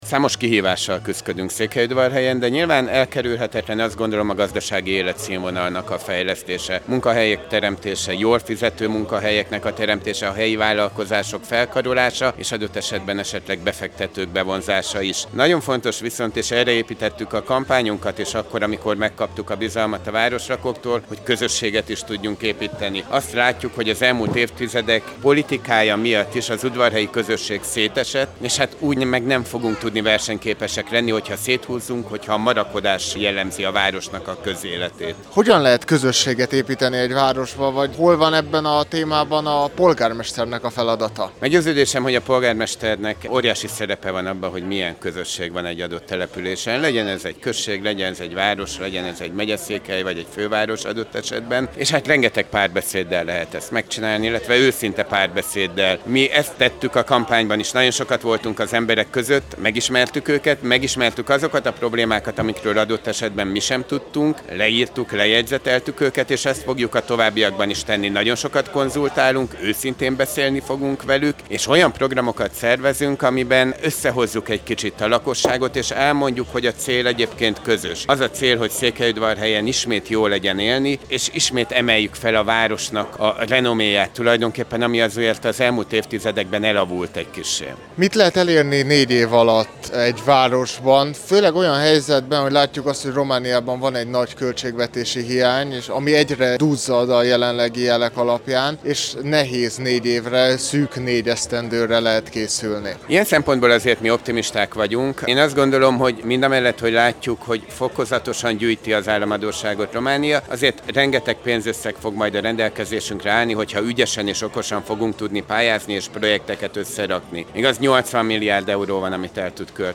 Kerekasztalbeszélgetés a székelyföldi városok polgármestereivel
A 33. Tusványoson tartott önkormányzati kerekasztal-beszélgetésen részt vett, Soós Zoltán, Marosvásárhely polgármestere, Korodi Attila, Csíkszereda polgármestere, Antal Árpád, Sepsiszentgyörgy polgármestere és Szakács-Paál István, Székelyudvarhely frissen megválasztott polgármestere.